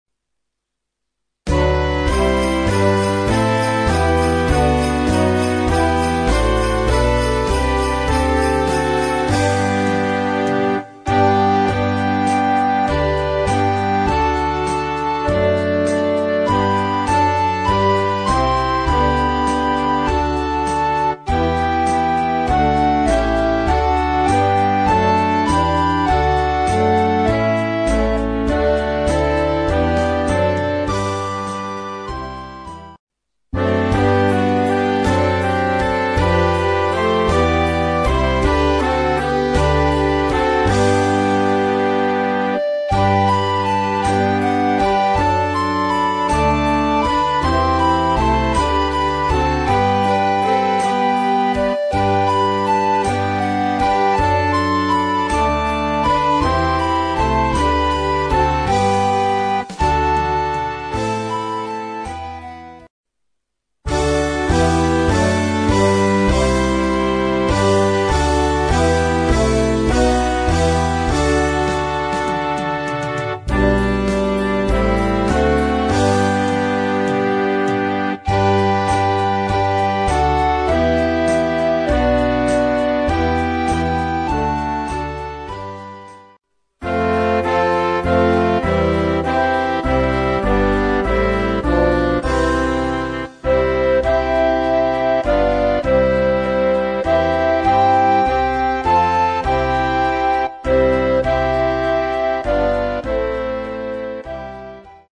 Inkl. Kinderchor ad lib.
Noten für flexibles Ensemble, 4-stimmig + Percussion.